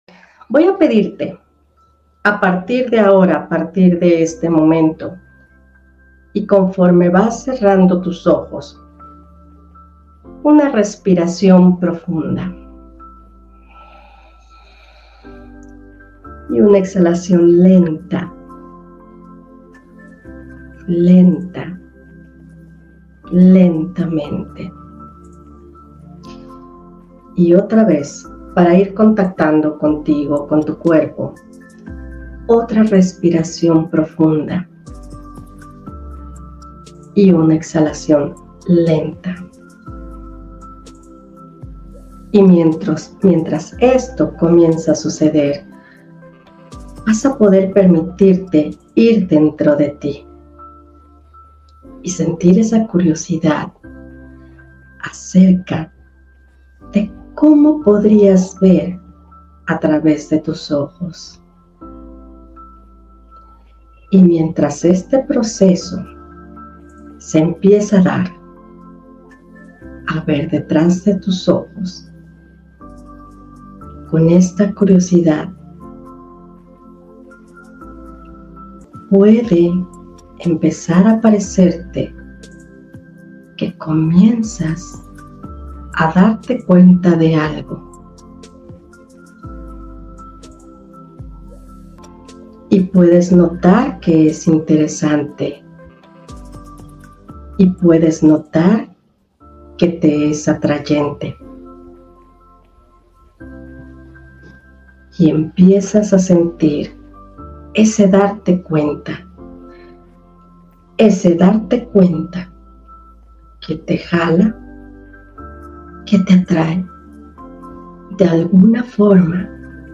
Hipnosis mejoraturelacion con la comida
Hipnosis-mejoraturelacion-con-la-comida.mp3